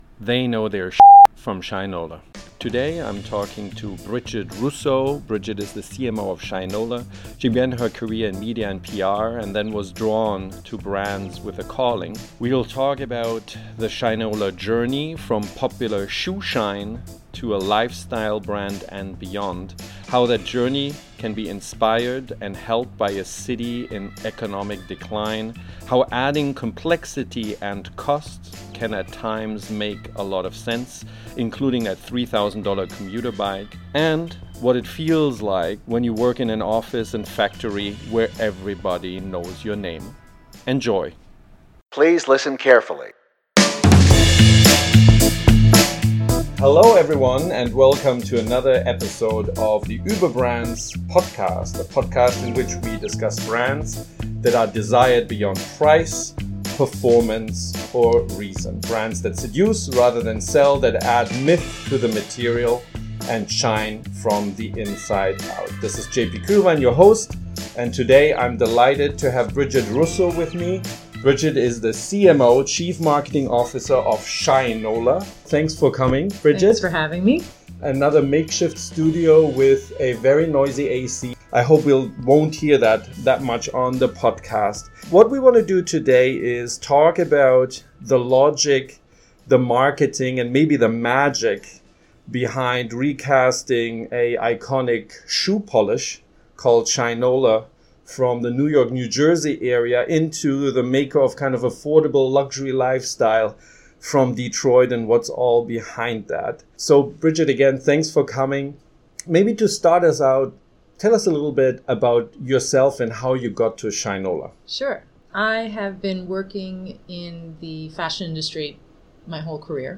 Shinola: From Shining Shoes to Lifestyle Star – Interview